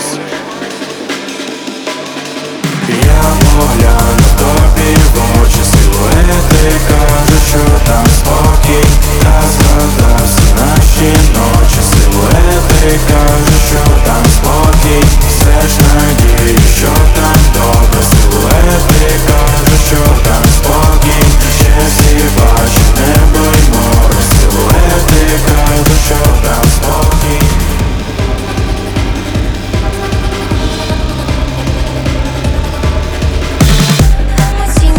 Жанр: Альтернатива / Украинские